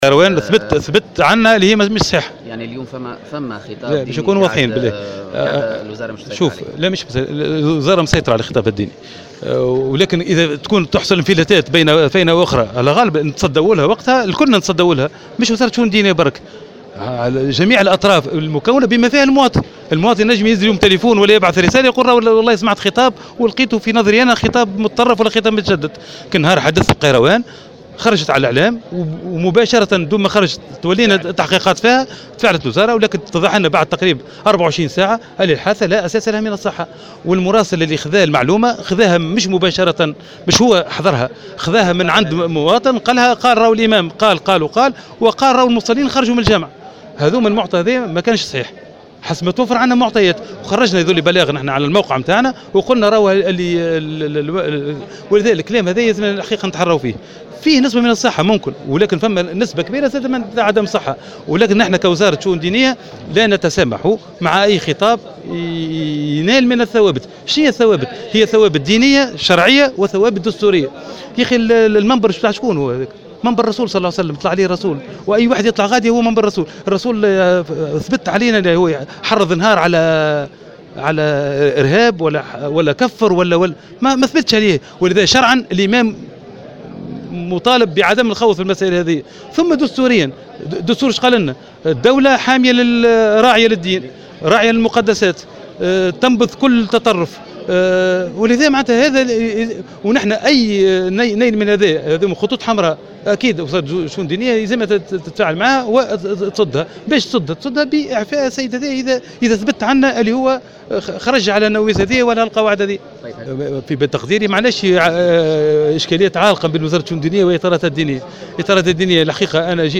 وقال في تصريحات صحفية على هامش زيارة إلى مدنين إن هذه الحادثة "لا أساس لها من الصحة" مشيرا إلى بيان رسمي أصدرته وزارته بعد التحقيق في الحادثة والذي تبين على اثره انه تم نقل الخبر من مواطن لم يحضر اصلا خطبة الجمعة ليوم 30 جوان الماضي. ودعا وزير الشؤون الدينية إلى ضرورة تحري مثل هذه المعلومات قبل نشرها.